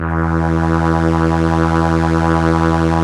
Index of /90_sSampleCDs/Roland LCDP09 Keys of the 60s and 70s 1/STR_ARP Strings/STR_ARP Ensemble